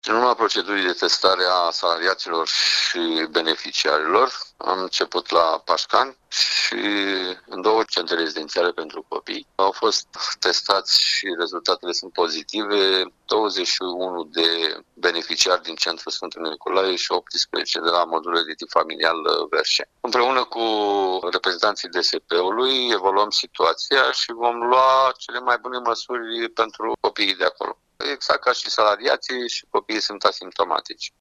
Directorul Direcției Generale pentru Protecția Drepturilor Copilului Iași, Ion Florin, a precizat că, începând de luni, au fost schimbate protocoalele în România și se efectuează testări medicale și la beneficiari, nu doar la angajați.